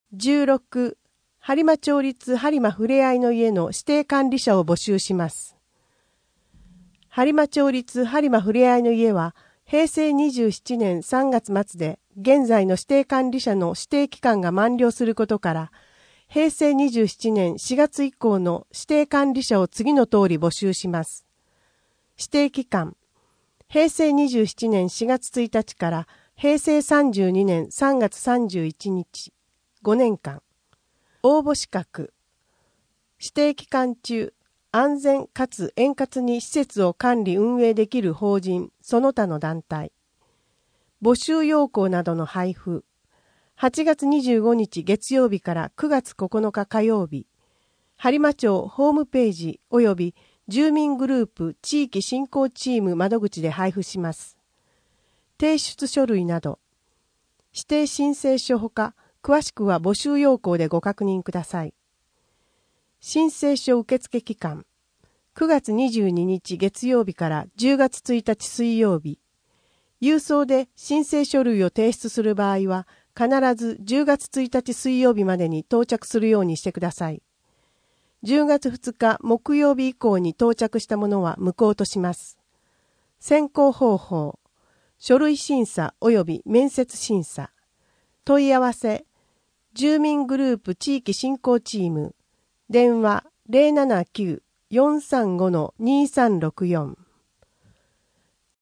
声の「広報はりま」9月号
声の「広報はりま」はボランティアグループ「のぎく」のご協力により作成されています。